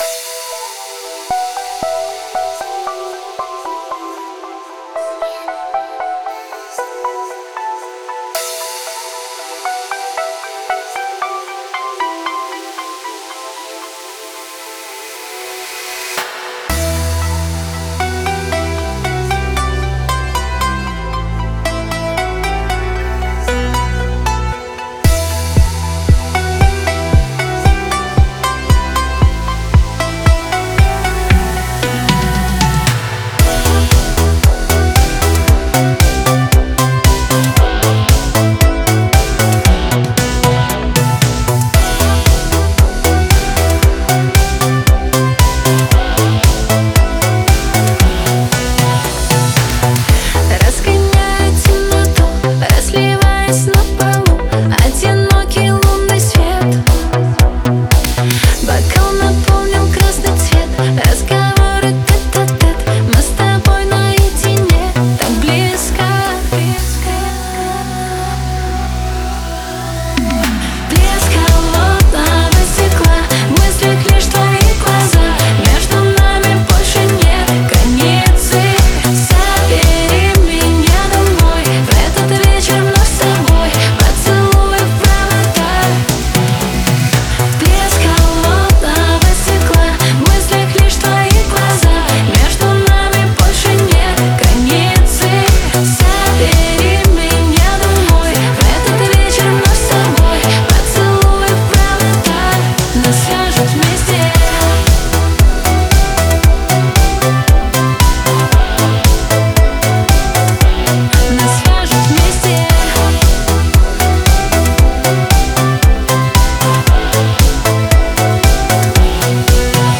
Танцевальная музыка
dance песни